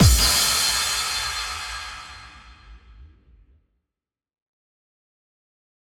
Impact 13.wav